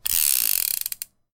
snd_6chamberreload.ogg